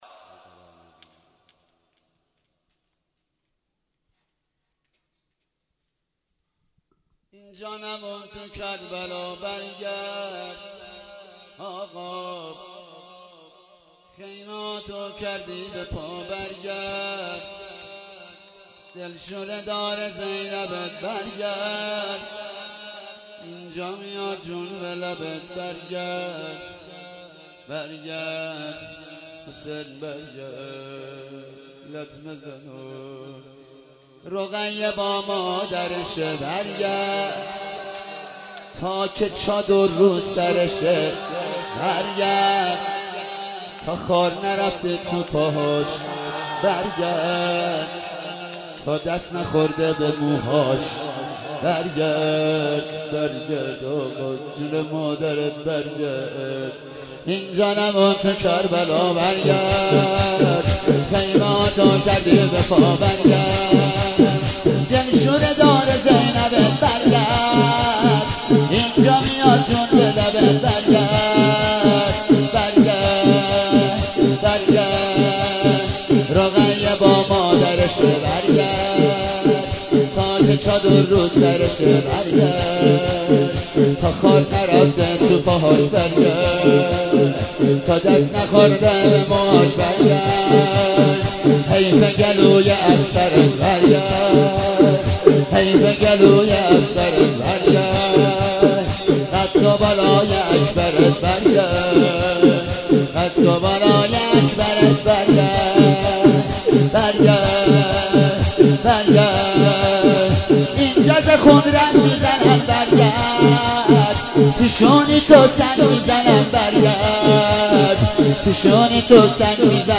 محرم95 شور